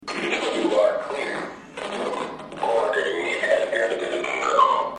Android_Activation_You_Are_Clear.mp3